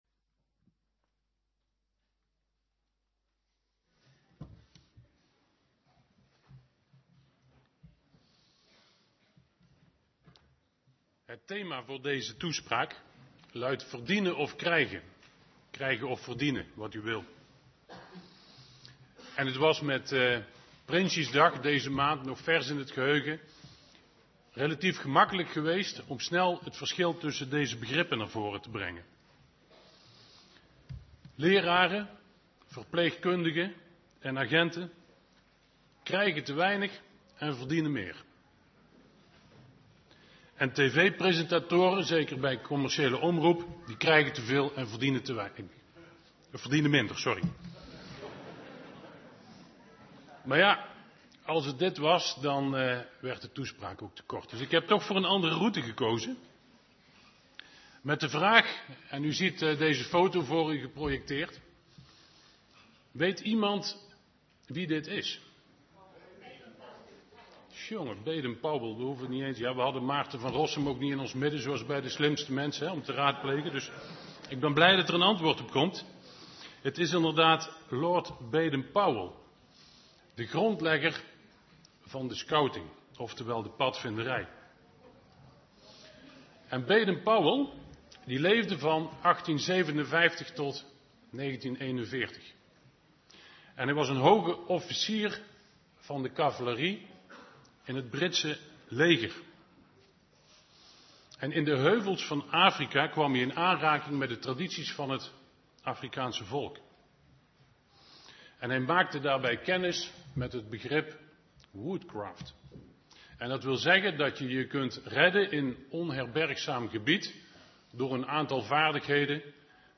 Toespraak tijdens de doopdienst.